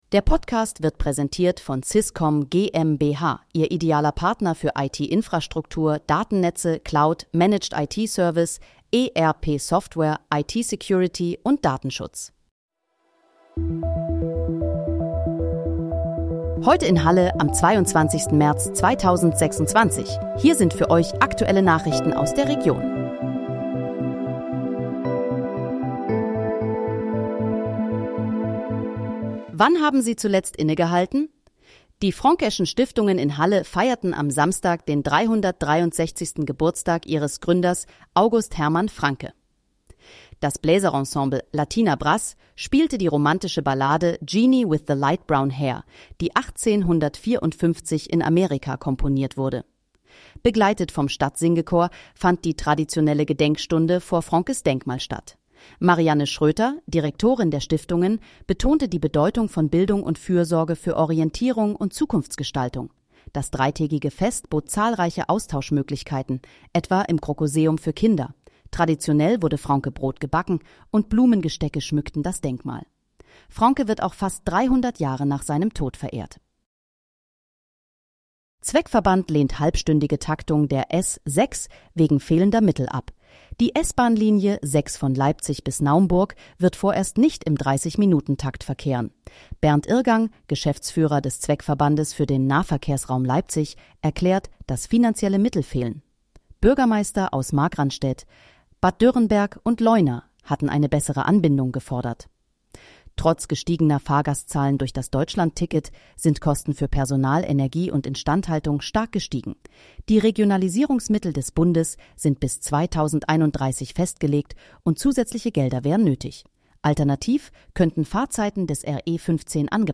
Heute in, Halle: Aktuelle Nachrichten vom 22.03.2026, erstellt mit KI-Unterstützung
Nachrichten